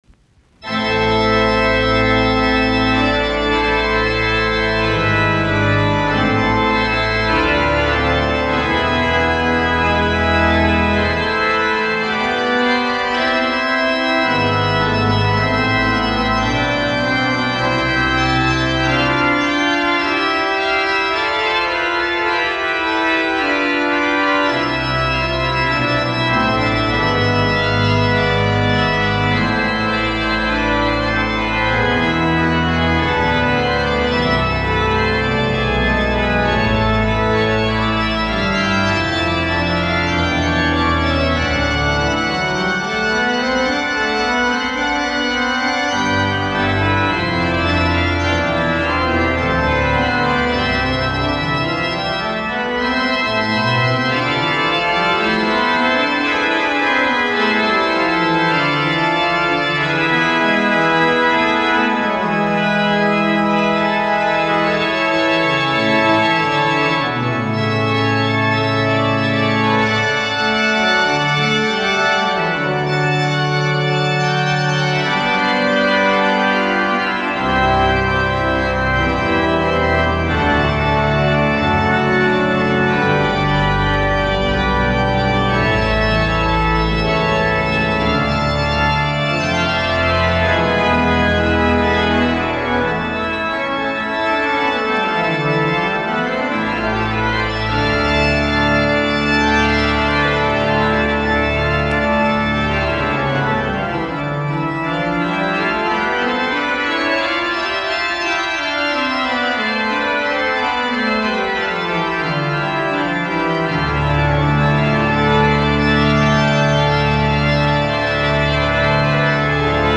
Toccatat, kosketinsoitin, OpOmn1/1:16
Soitinnus: Urut.
Haag, Grote of St. Jacobskerk.